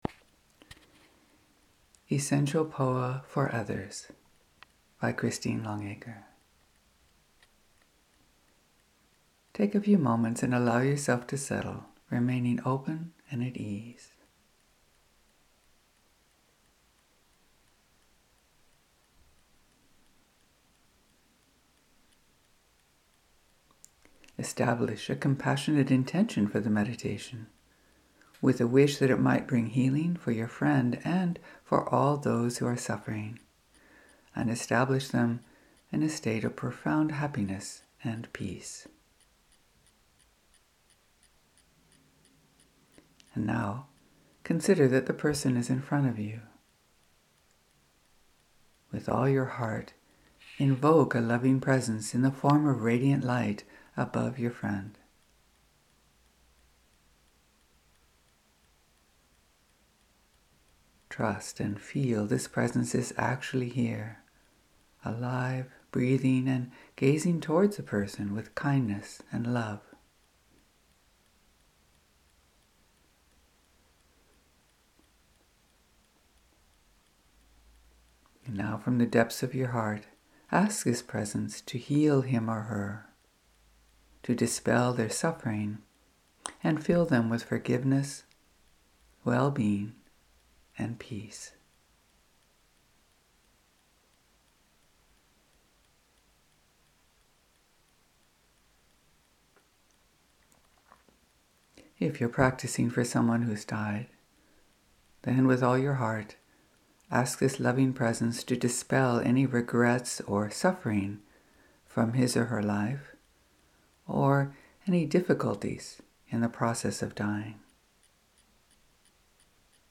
Guided Meditations for you